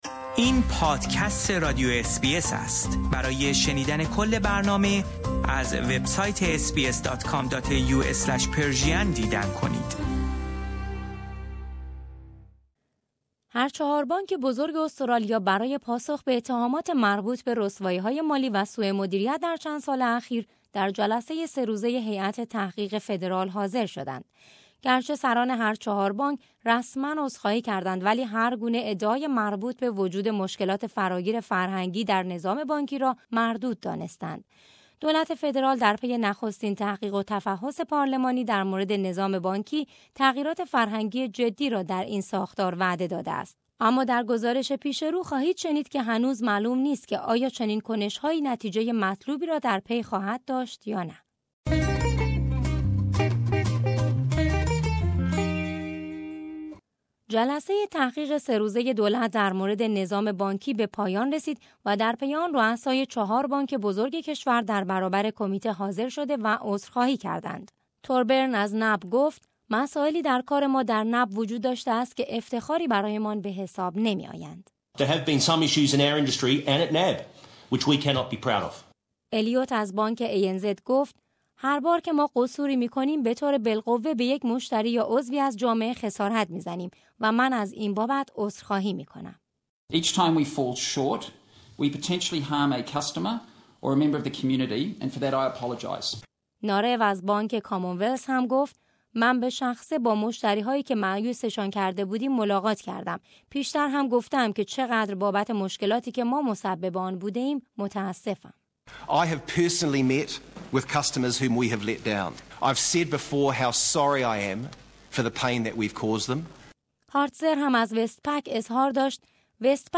اما در گزارش پیش رو خواهید شنید که هنوز معلوم نیست که آیا چنین کنشهایی نتیجۀ مطلوبی را در پی خواهد داشت یا نه.